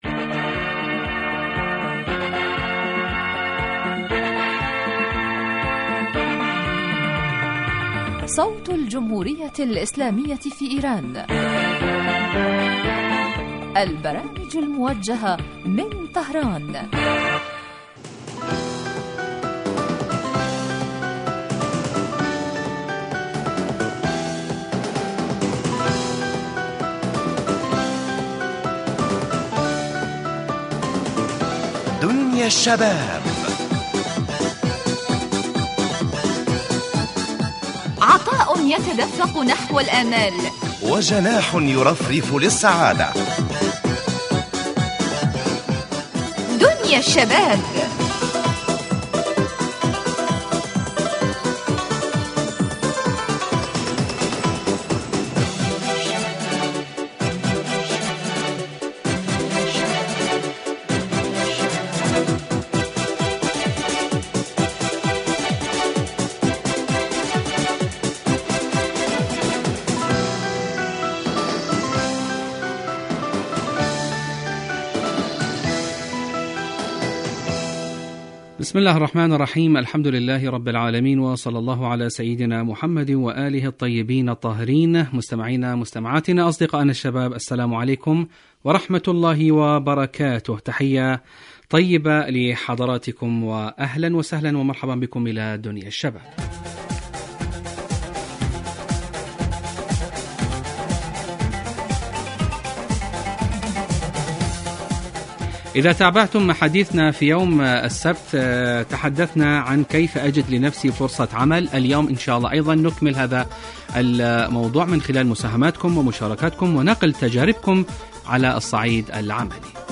برنامج اجتماعي غني بما یستهوی الشباب من البلدان العربیة من مواضیع مجدیة و منوعة و خاصة ما یتعلق بقضایاهم الاجتماعیة وهواجسهم بالتحلیل والدراسة مباشرة علی الهواء